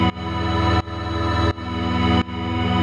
Index of /musicradar/sidechained-samples/170bpm
GnS_Pad-dbx1:2_170-E.wav